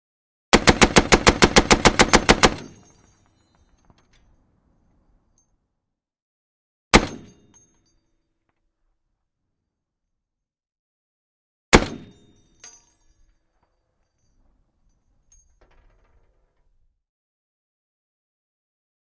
Tank Machine Gun: Fire ( Int. ); Series Of Machine Gun Fires With Clink Of Shells Falling To Hard Ground. Two Single Shots Fired After Series Firing. Close Perspective.